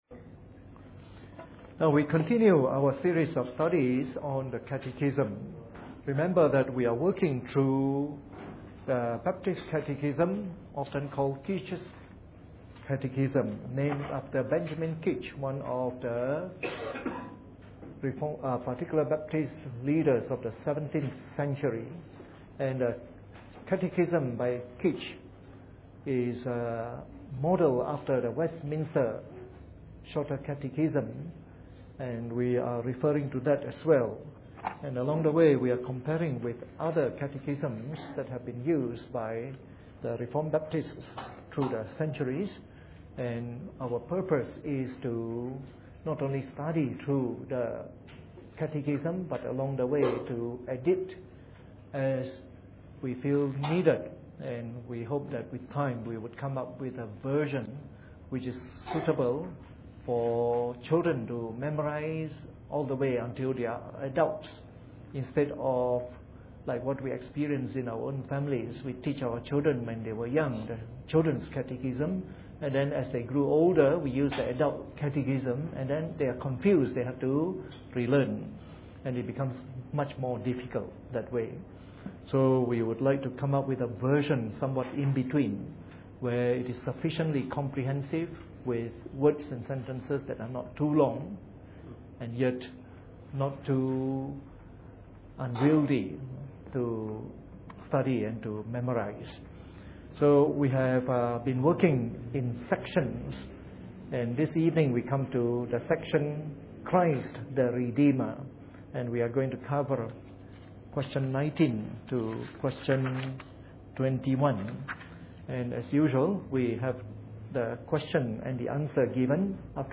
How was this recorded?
Preached on the 9th of March 2011 during the Bible Study from our current series on the Shorter Catechism.